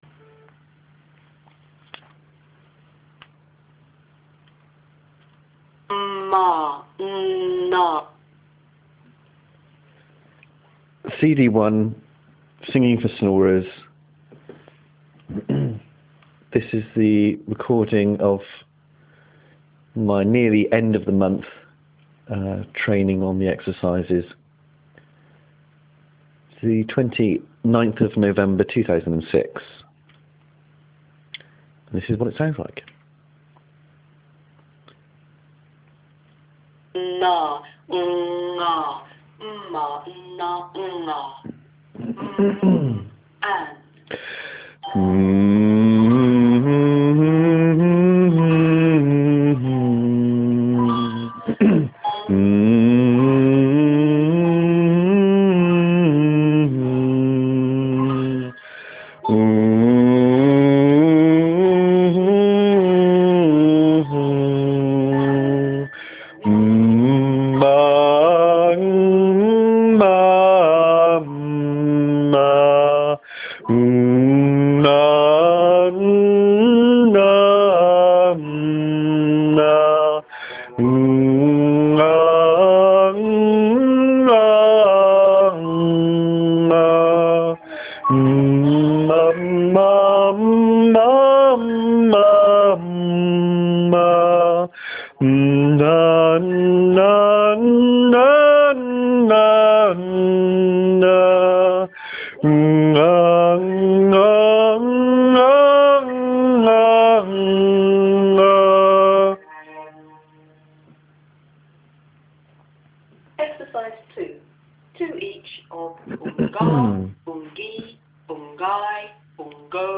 The awful before and the not so bad afters A recording of the Singing for snorers